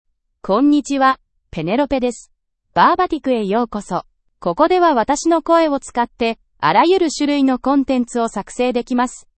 PenelopeFemale Japanese AI voice
Penelope is a female AI voice for Japanese (Japan).
Voice sample
Listen to Penelope's female Japanese voice.
Penelope delivers clear pronunciation with authentic Japan Japanese intonation, making your content sound professionally produced.